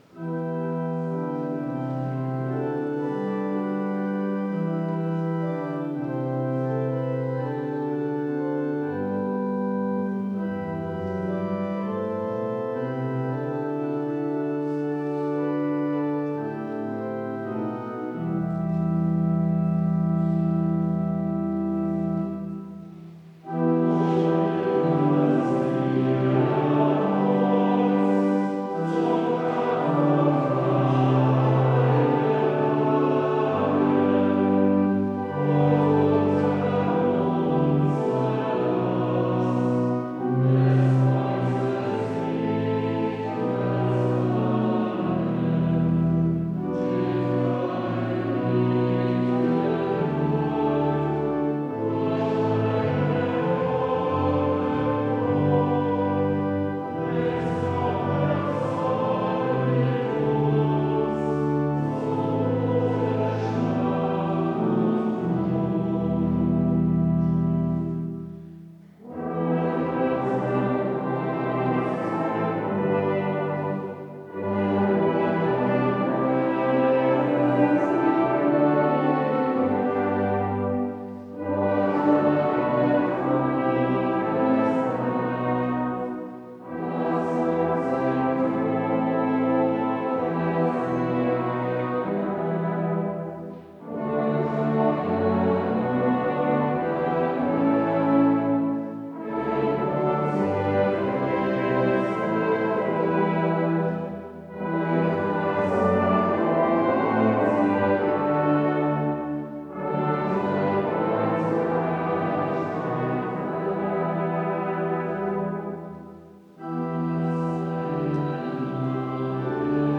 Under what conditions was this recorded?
Audiomitschnitt unseres Gottesdienstes am Palmsonntag 2025.